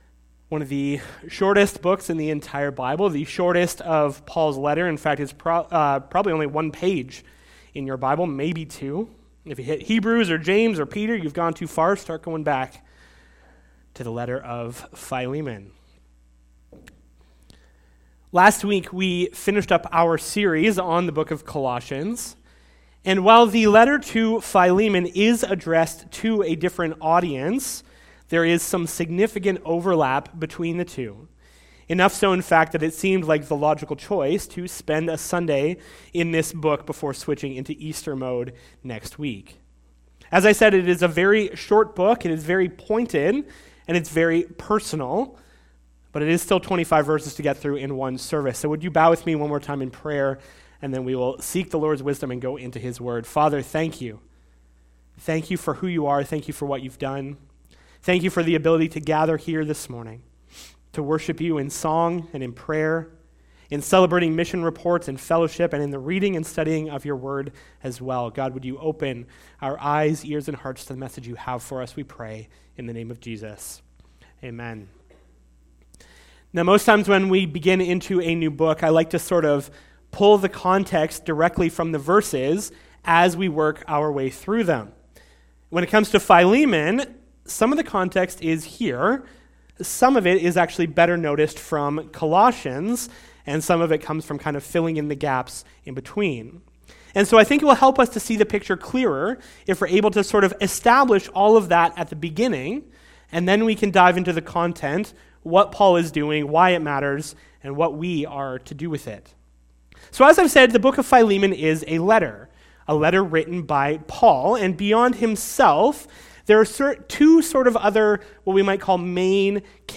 Sermon Audio and Video Beloved Brotherhood